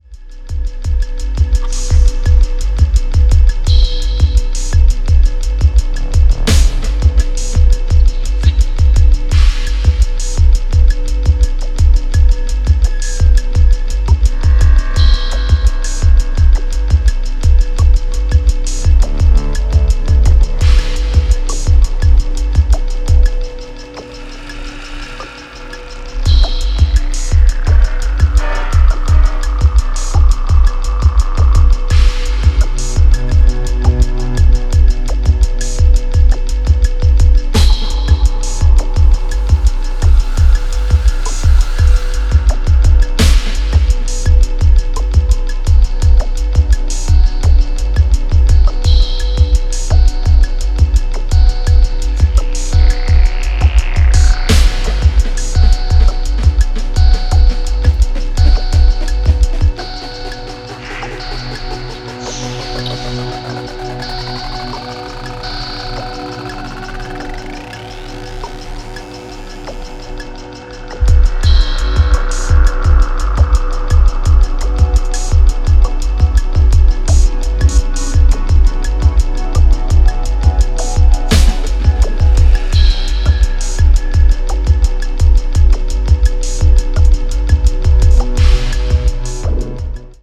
ダンスホールを解体したようなアブストラクト・バウンス
非常に先進的、かつ神聖さすら感じさせるDNB表現を堂々開陳